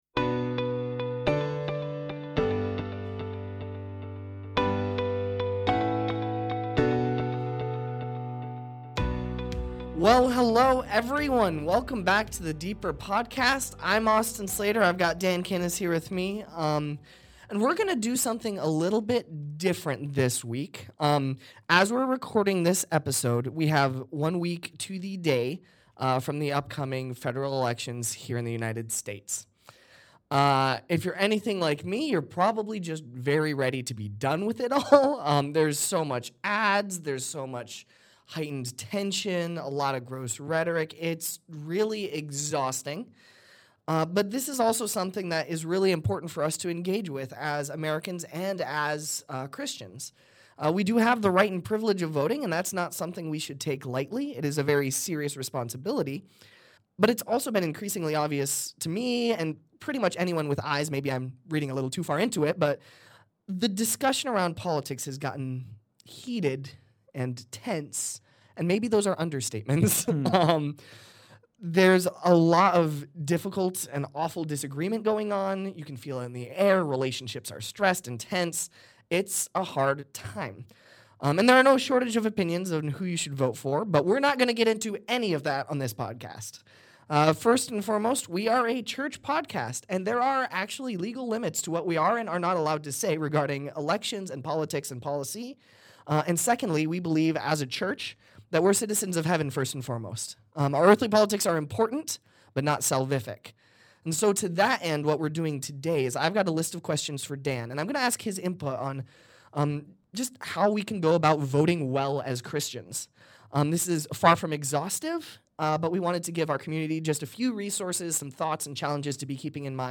Brief apology: There were some errors during production that resulted in some inconsistent audio speeds. Though we did our best to rectify, there's only so much we can do short of re-recording the whole episode.